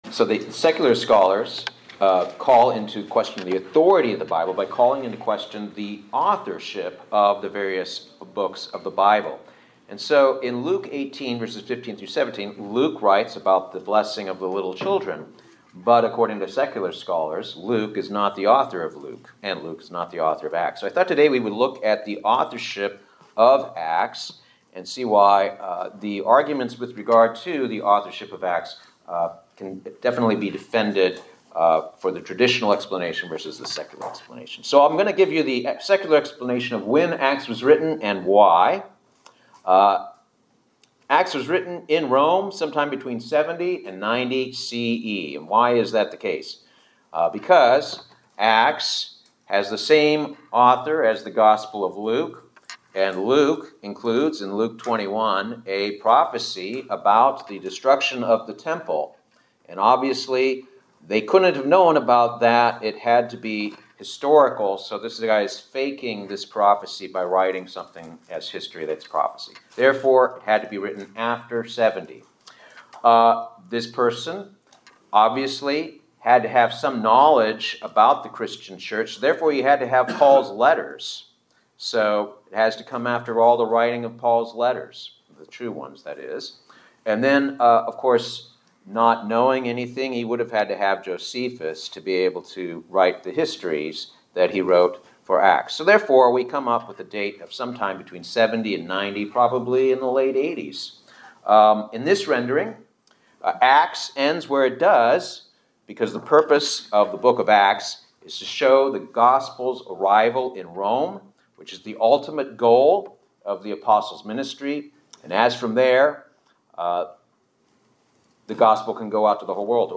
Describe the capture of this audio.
Given in Buford, GA